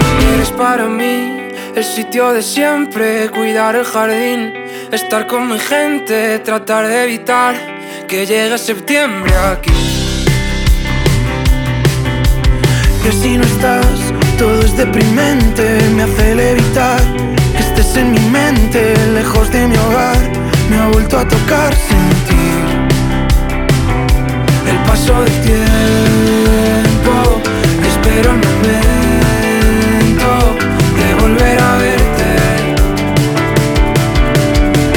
Indie Pop Alternative
Жанр: Поп музыка / Альтернатива